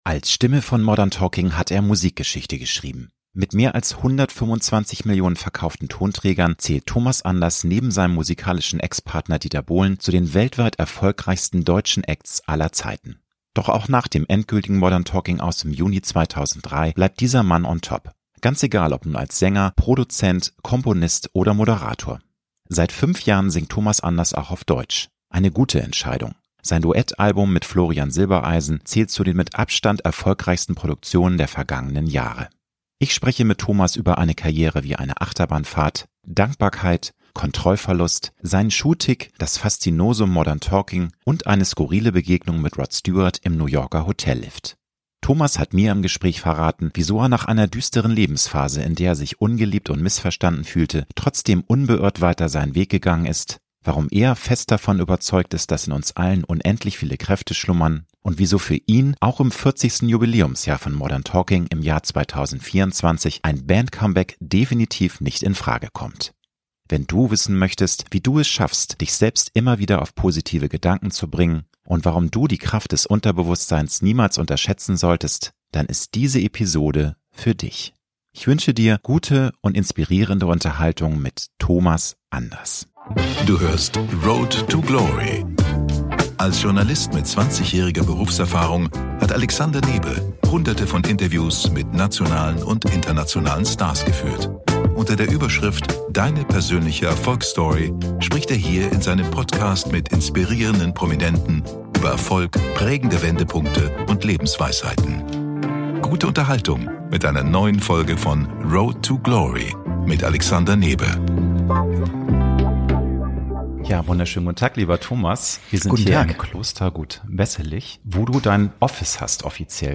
Promi-Talk
Ich spreche mit Thomas über eine Karriere wie eine Achterbahnfahrt, Dankbarkeit, Kontrollverlust, seinen Schuhtick, das Faszinosum Modern Talking und eine skurrile Begegnung mit Rod Stewart im New Yorker Hotellift.